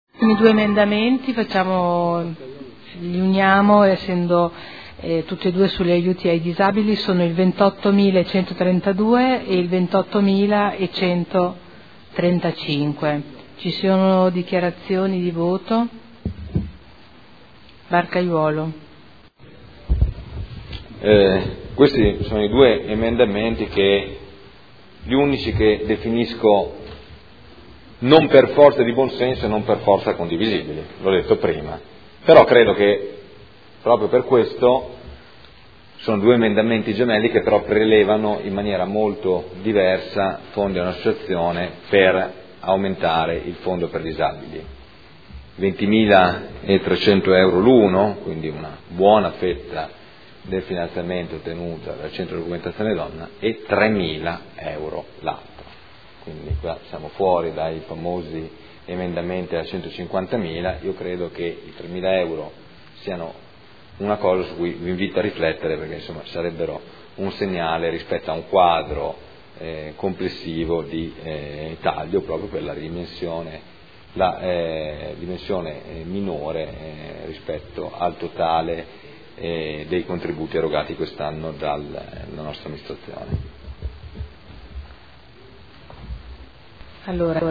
Seduta del 13 marzo. Dichiarazioni di voto su singoli emendamenti o complessive